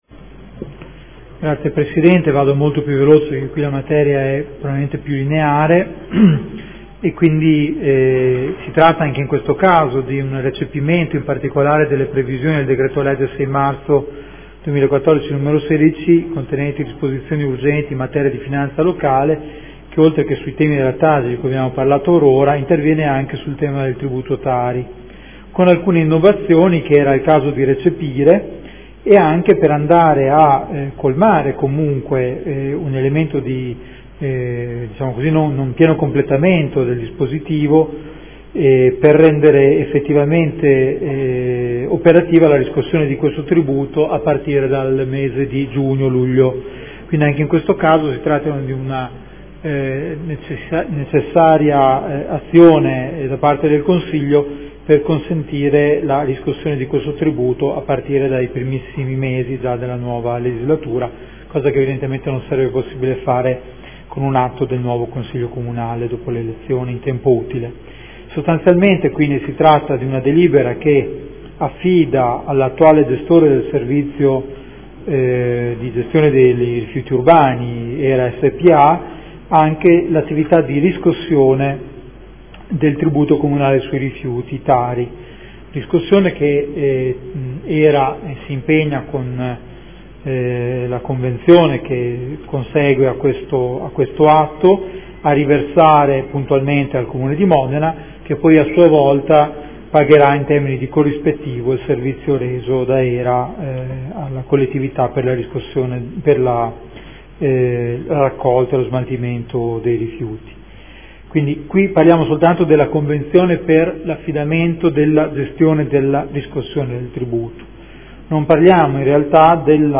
Giuseppe Boschini — Sito Audio Consiglio Comunale